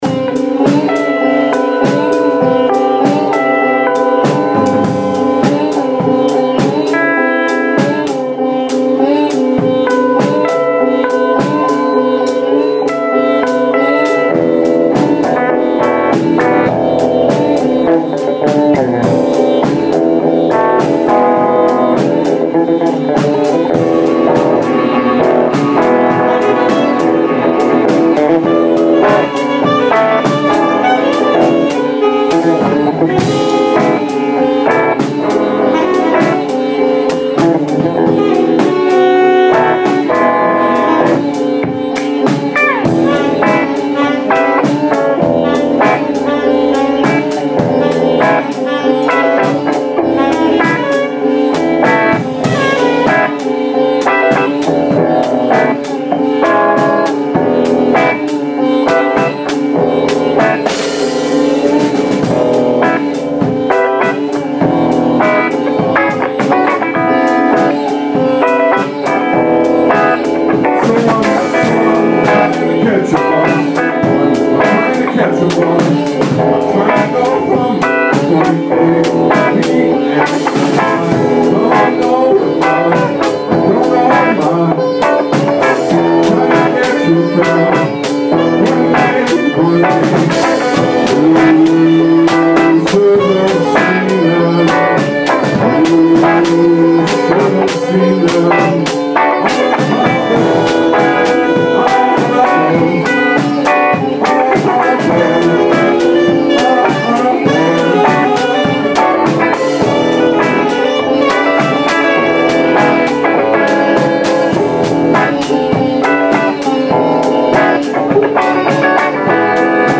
sitar
giutar/bass
drums
sax